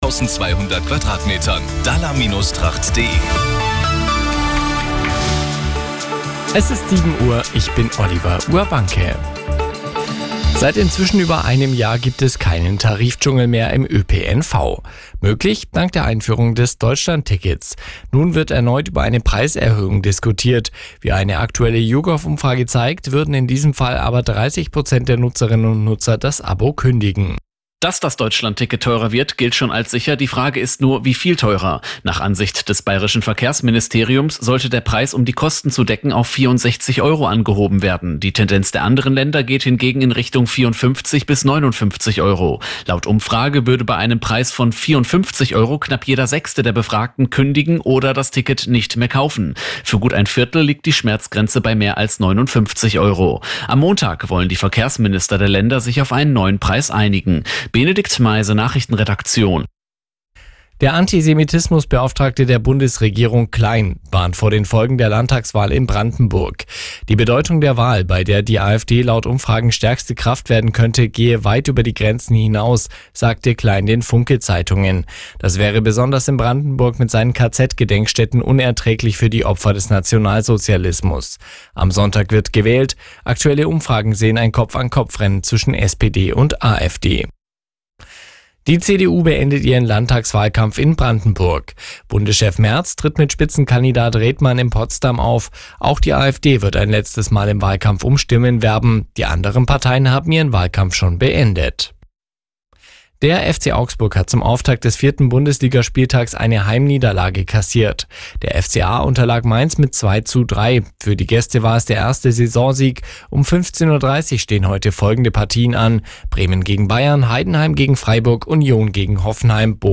Die Radio Arabella Nachrichten von 10 Uhr - 21.09.2024